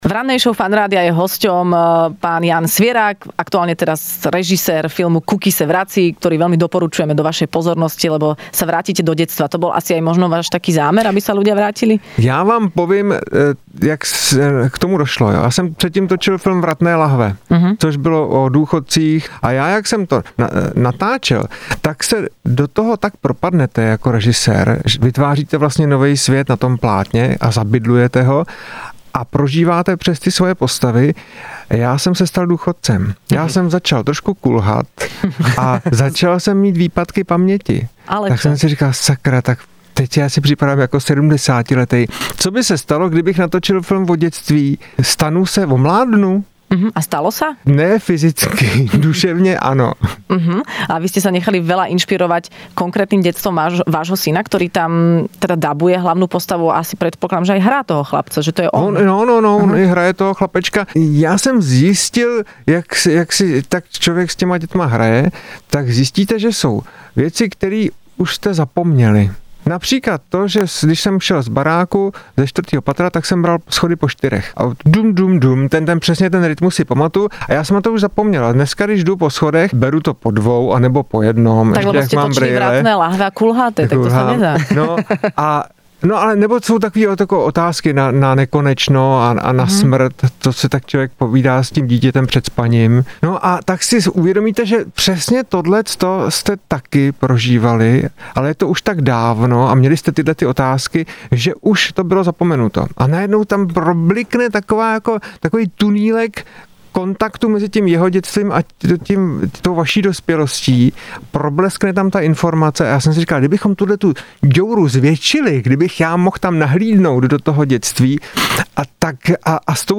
Hosťom v rannej šou Fun rádia bol režisér Jan Svěrák, ktorý porozprával o svojom novom filme Kuky se vrací...